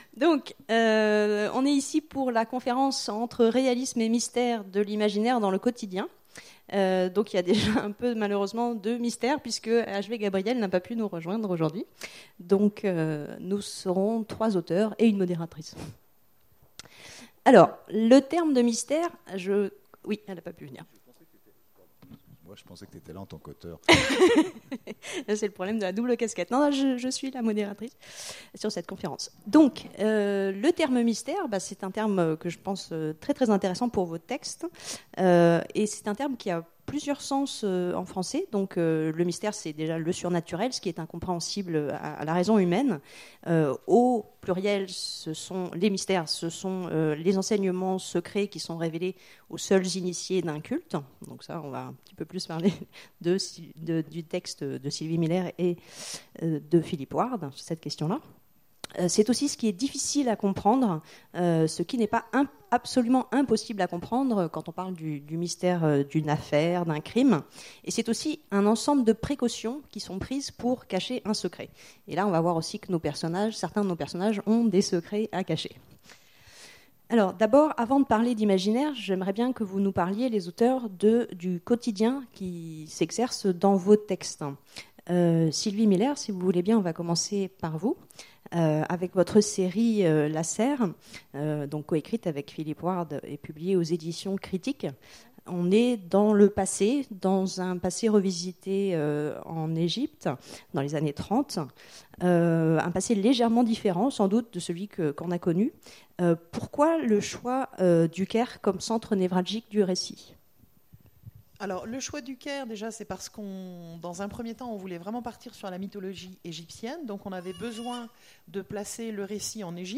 Imaginales 2016 : Conférence Entre réalisme et mystère…